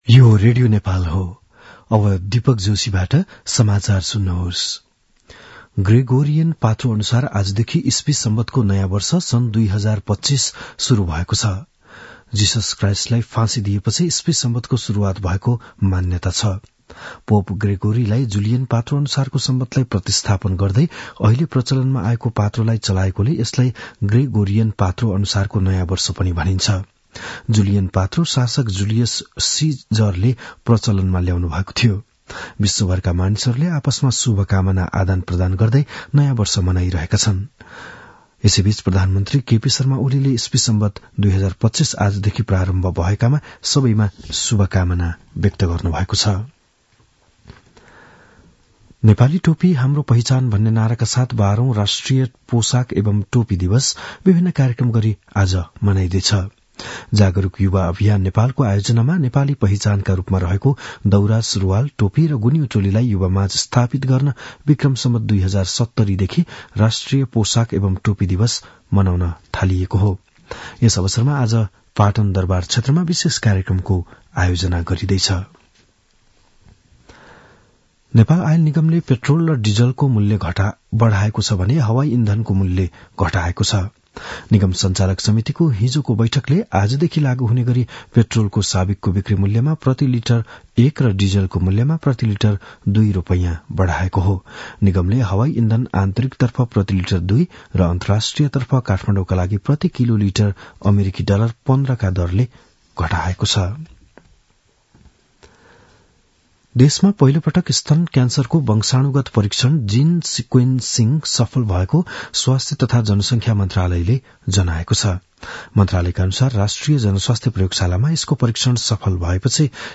बिहान ११ बजेको नेपाली समाचार : १८ पुष , २०८१
11-am-nepali-news-.mp3